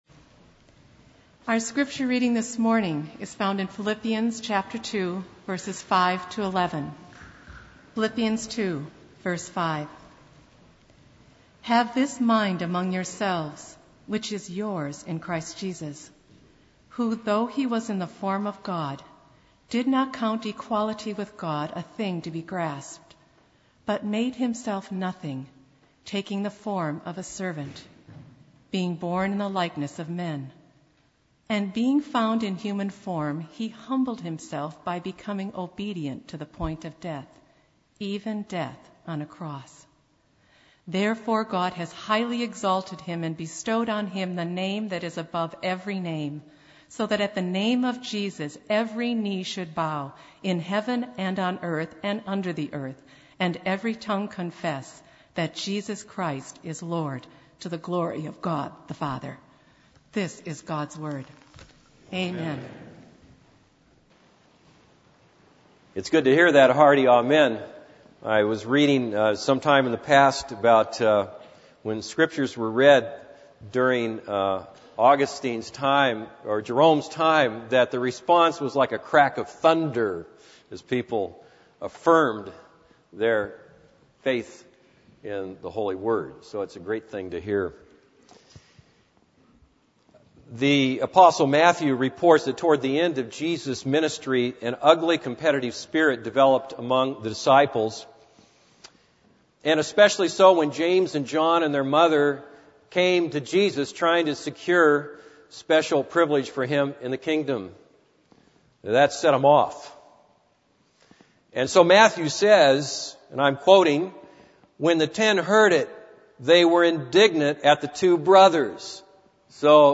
This is a sermon on Philippians 2:5-8.